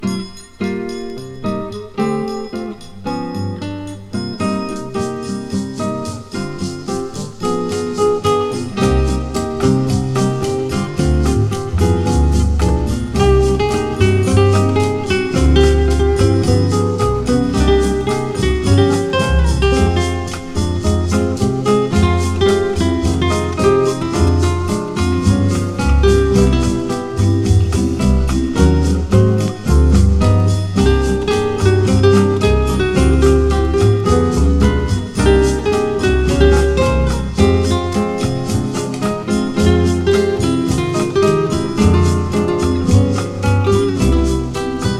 本盤もまた、多様な音使いで刺激いっぱい。
Jazz, Pop, Easy Listening, Lounge　USA　12inchレコード　33rpm　Mono